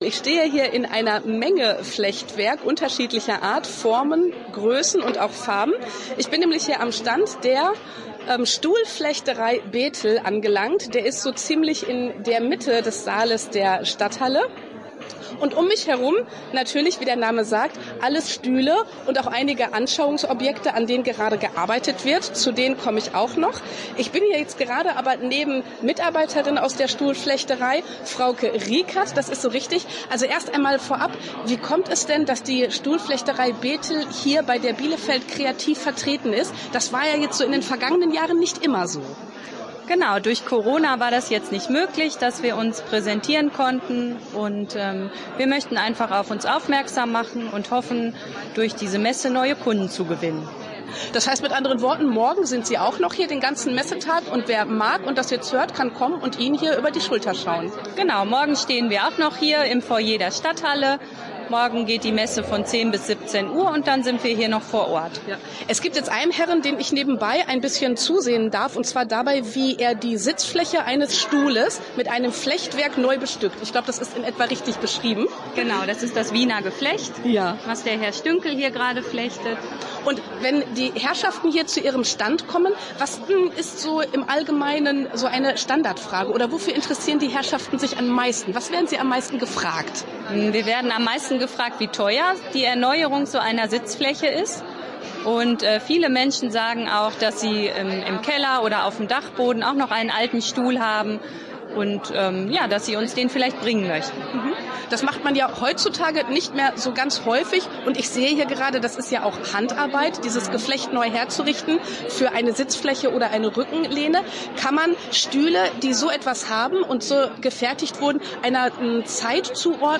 Stuhlflechterei Bethel auf der Messe „Bielefeld Kreativ“ in der Stadthalle Bielefeld
berichtete für unser Samstagsmagazin von dort